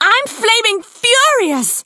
amber_hurts_vo_04.ogg